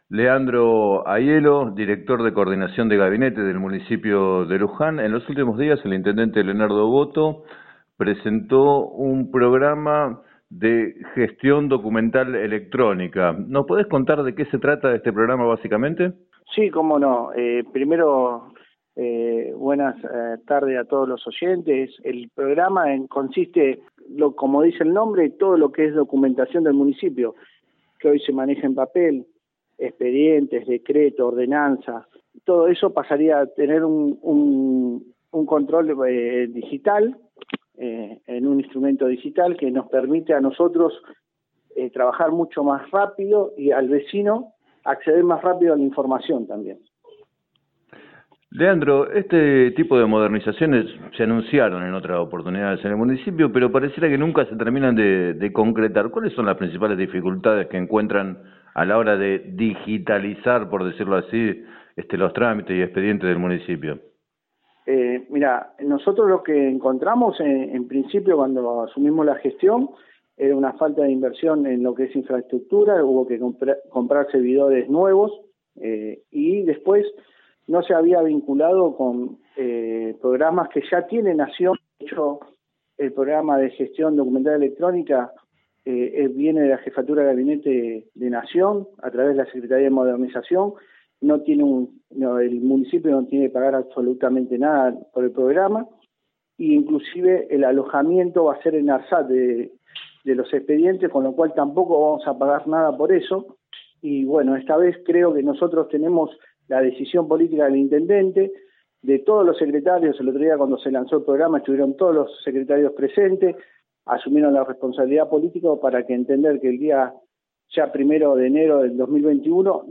El Director de Coordinación de Gabinete, Leandro Aiello, explicó a Radio Líder 97.7 los alcances del programa, que incluirá un proceso de capacitación para trabajadores municipales.